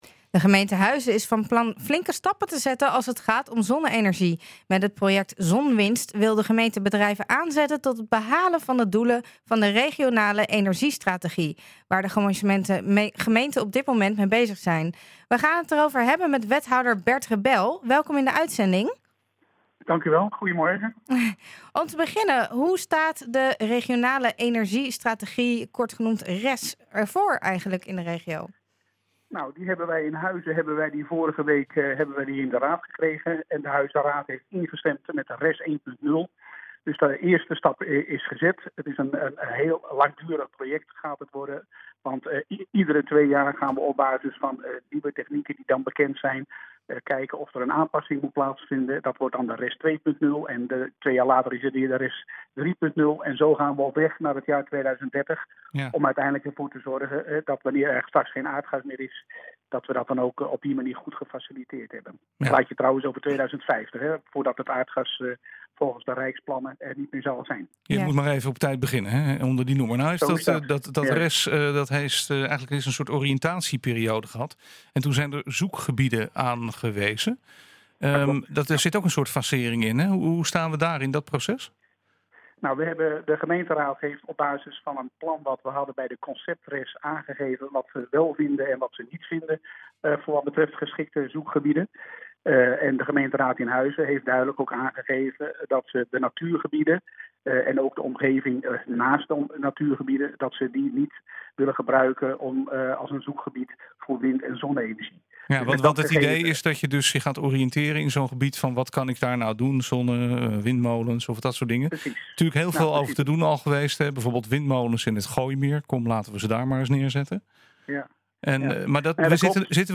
Wethouder Bert Rebel licht in het radioprogramma NH Gooi Zaterdag de plannen toe.